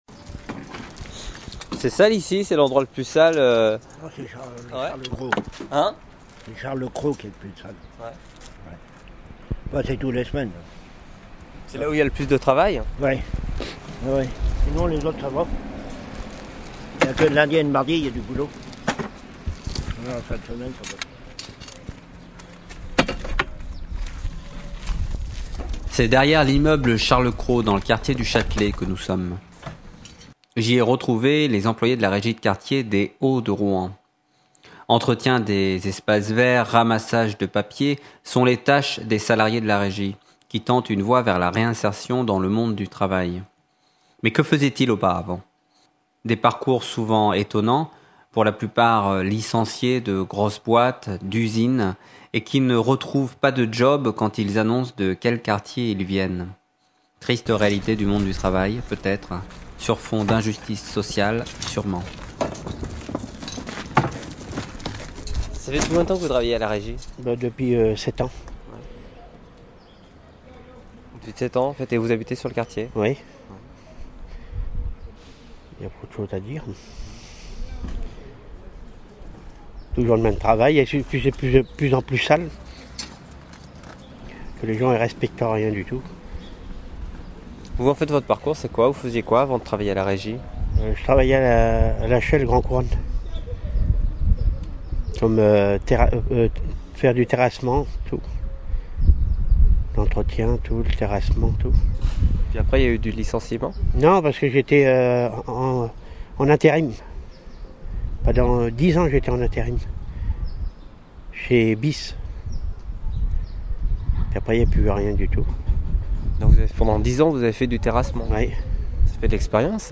Un extrait de reportage sur la régie de quartier des hauts de Rouen qui vise à offrir un travail à des chômeurs de longues durée.